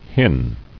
[hin]